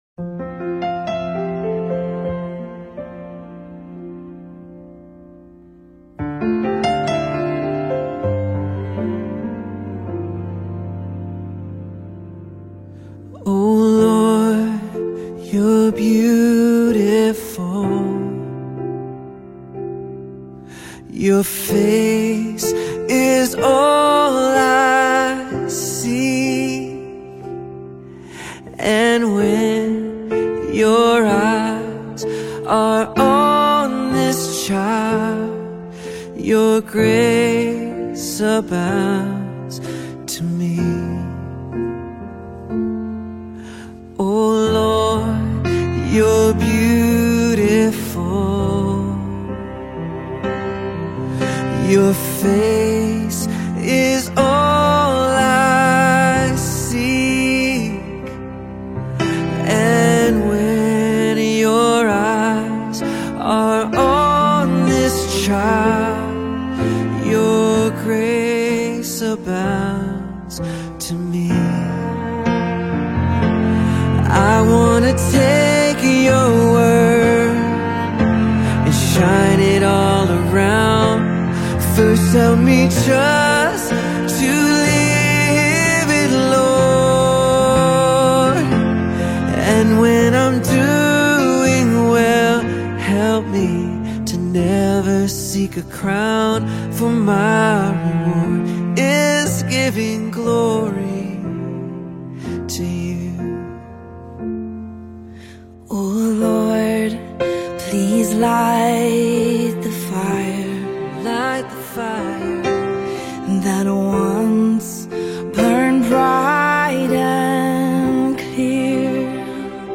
contemporary Christian music
a song of praise worship